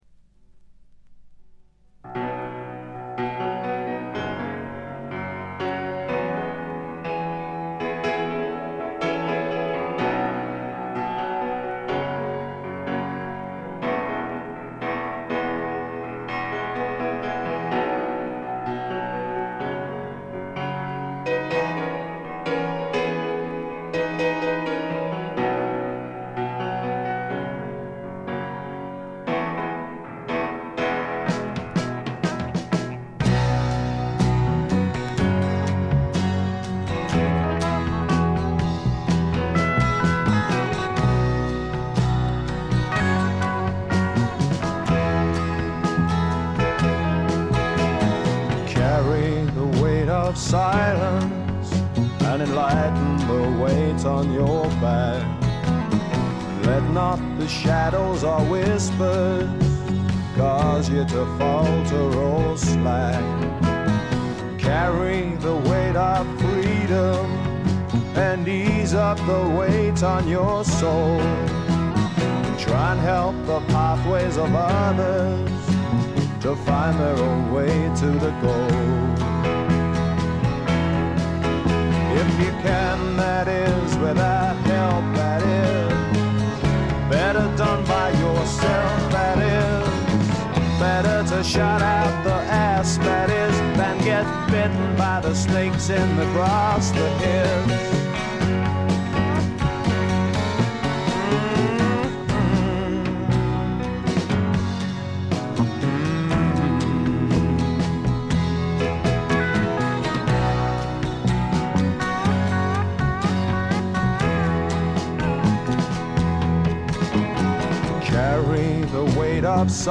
ほとんどノイズ感無し。
というわけでスモーキーなヴォーカルが冴える霧の英国シンガー・ソングライターの金字塔作品です。
試聴曲は現品からの取り込み音源です。